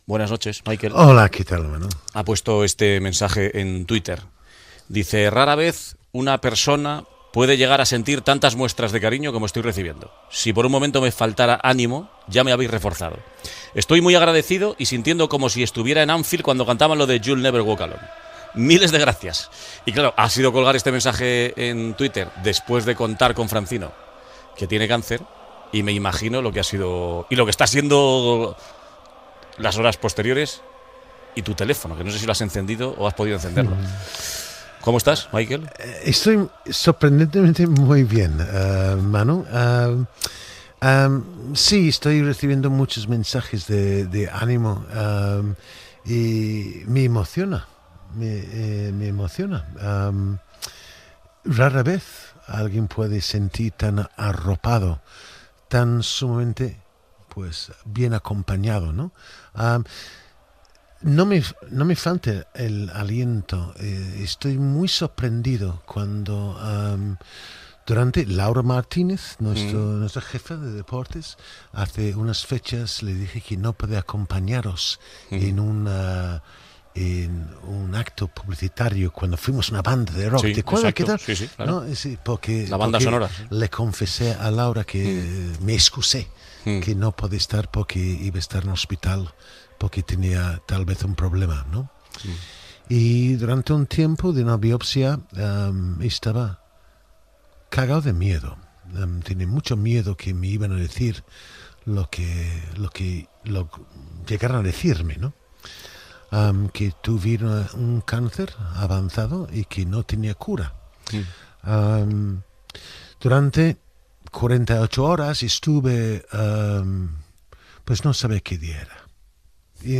Entrevista a l'exfutbolista i comentarista esportiu Michael Robinson el dia segünt que comunqués al programa "La ventana" que tenia un melanoma amb metàstasi avançada
Esportiu